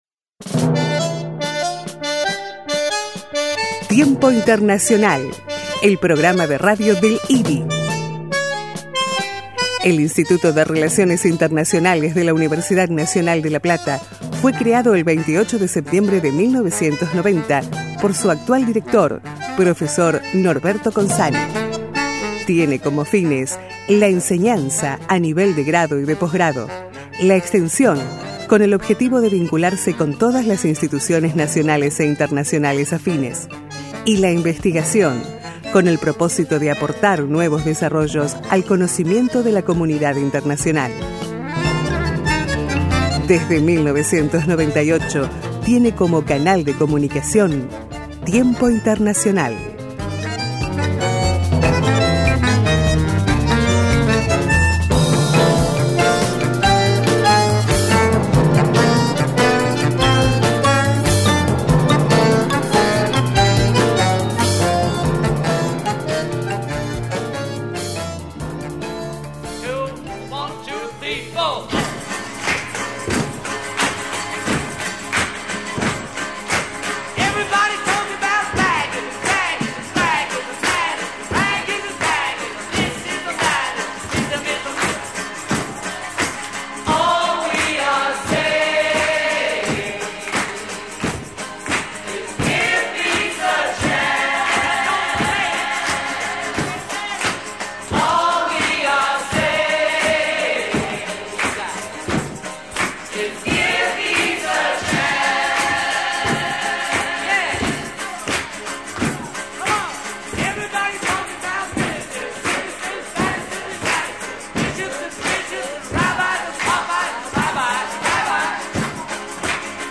Tiempo Internacional entrevistó